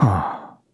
voices / heroes / en
Kibera-Vox_Think.wav